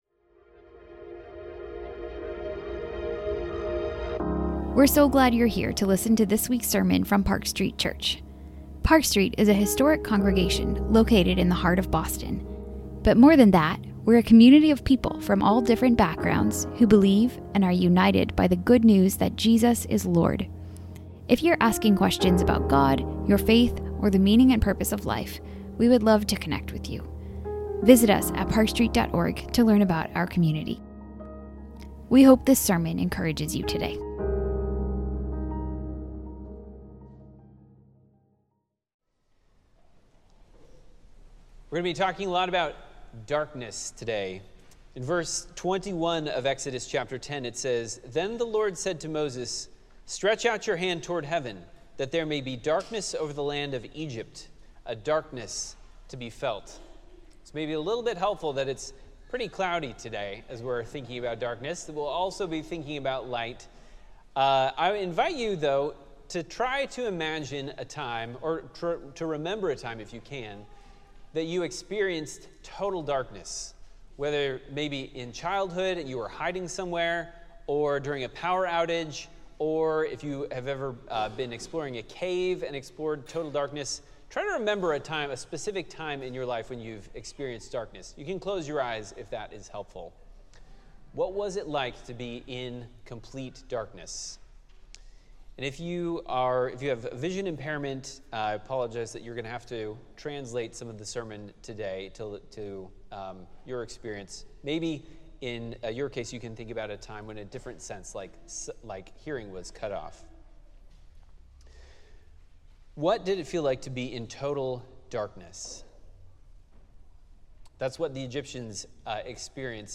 Sermons | Park Street Church